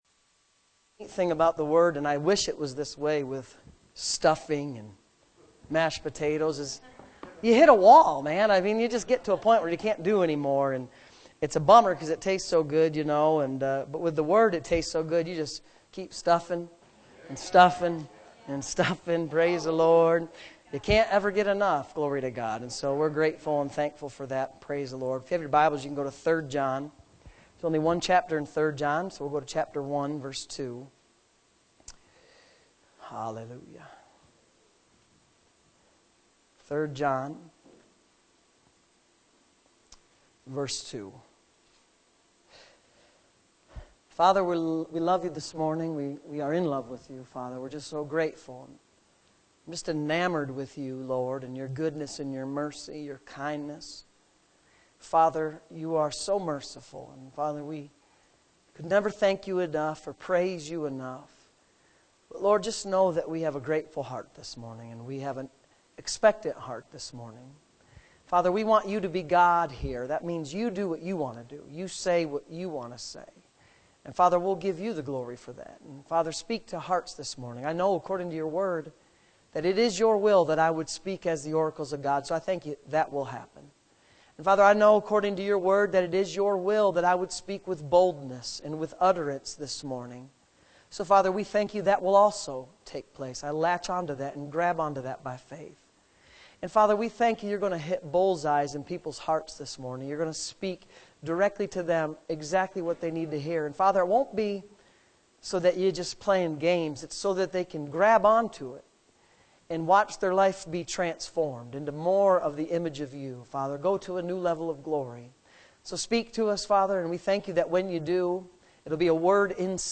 Sunday Morning Services